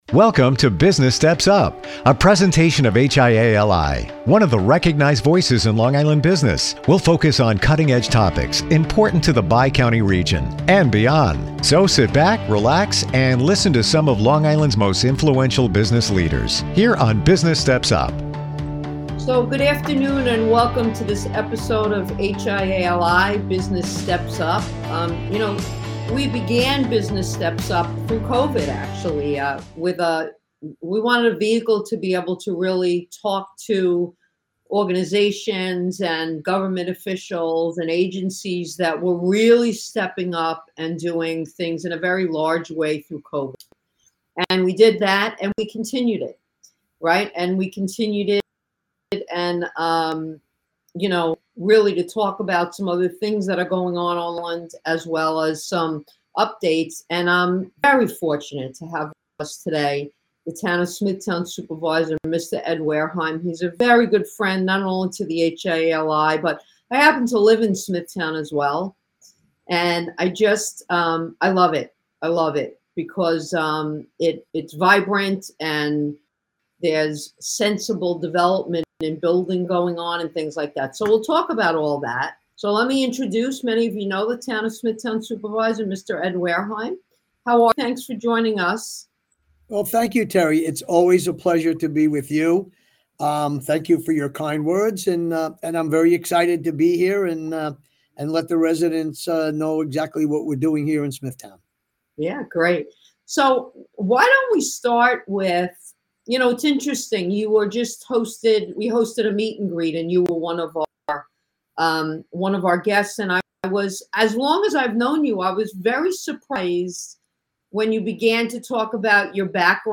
Leading Smithtown: A Conversation with Town Supervisor Ed Wehrheim